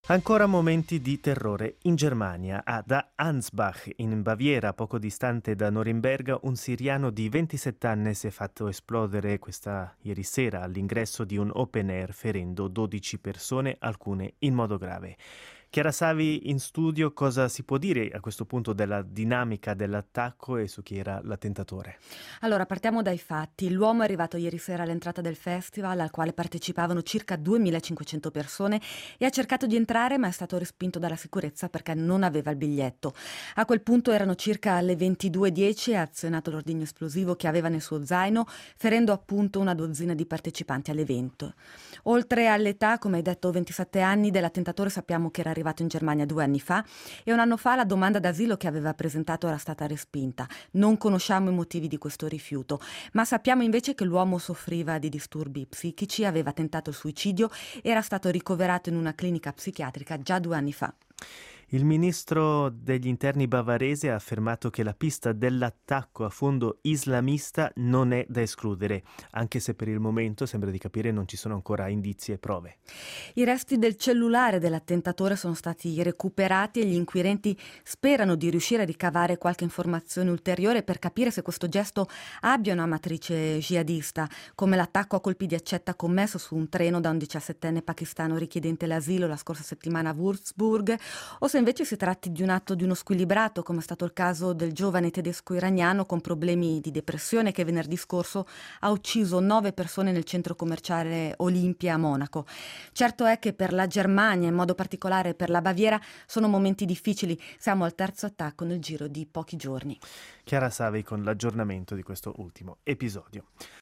Il servizio in diretta